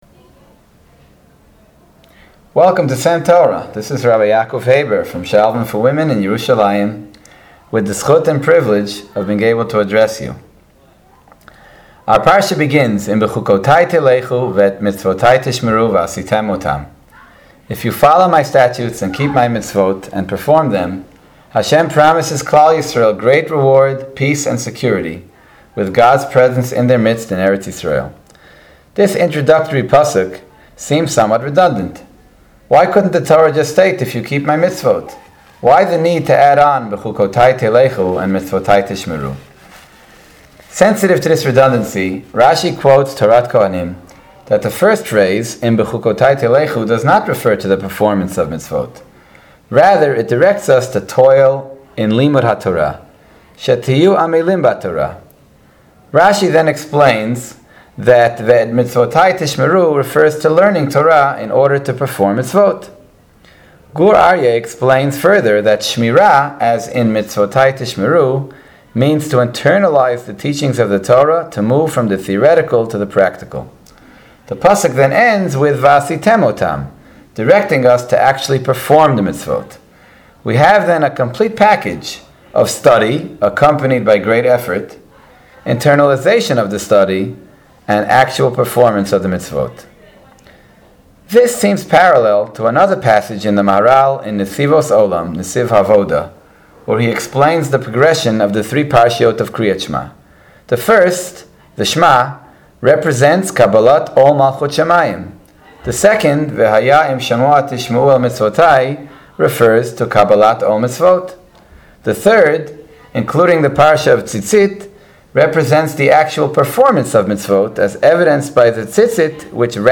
S.E.M. Torah is a series of brief divrei Torah delivered by various members of the faculty of Sha’alvim for Women.